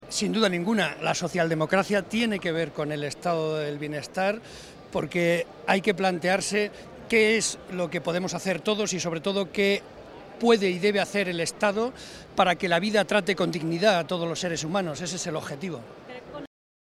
José María Barreda en la Conferencia Política del PSOE
Cortes de audio de la rueda de prensa